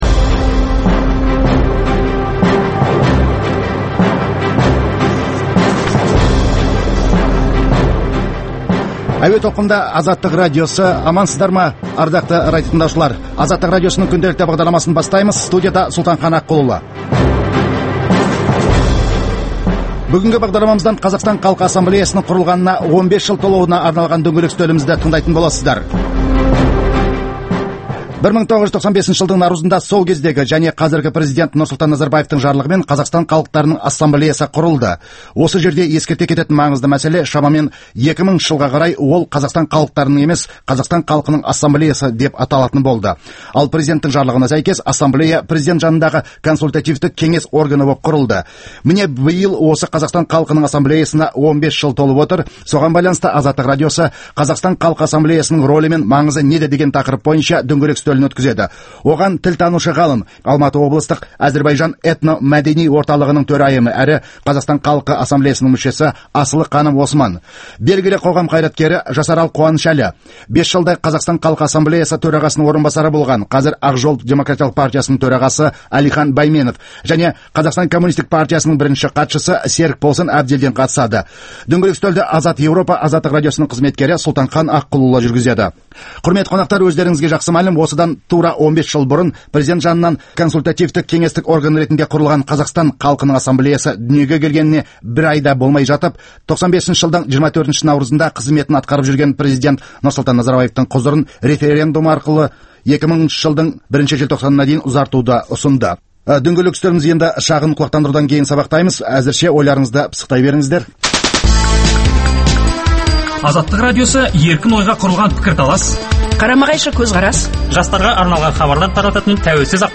Дөңгелек үстел